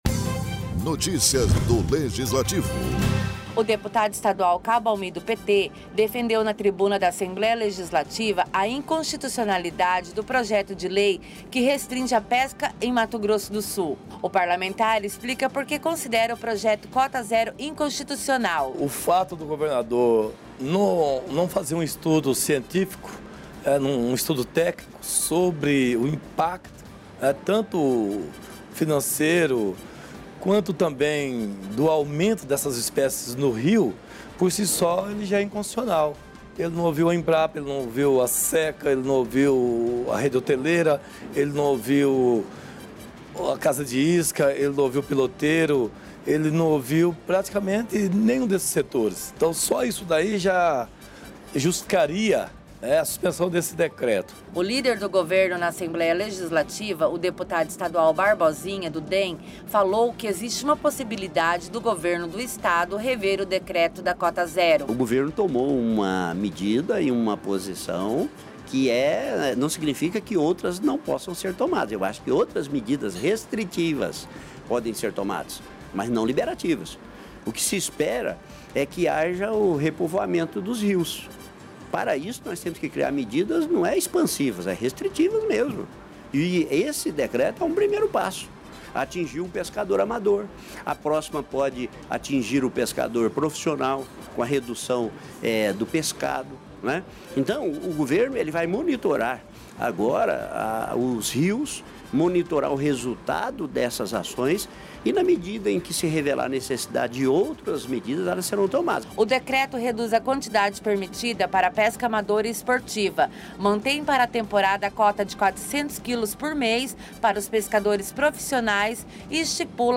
Na tribuna da Casa de Leis nesta quinta-feira (4), o deputado estadual Cabo Almi (PT) afirmou que o decreto que altera limites da pesca amadora e institui Cota Zero a partir de 2020 em Mato Grosso do Sul é inconstitucional.